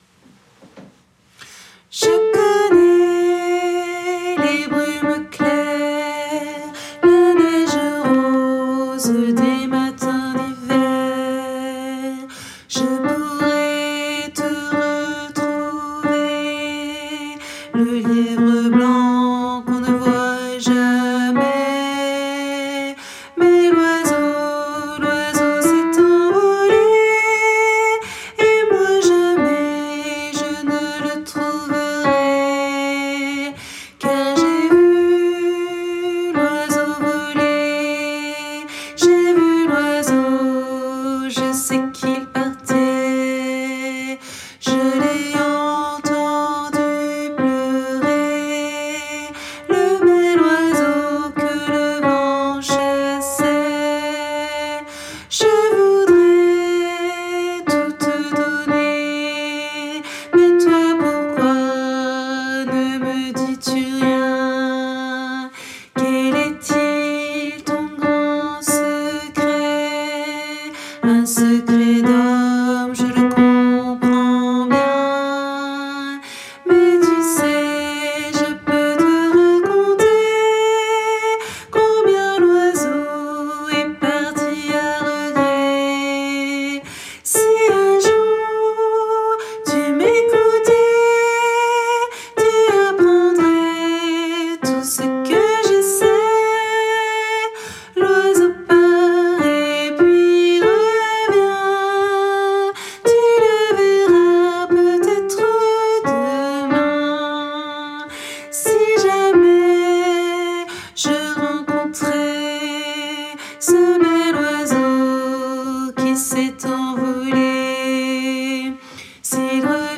- Oeuvre pour choeur à 4 voix mixtes (SATB)
MP3 versions chantées
Alto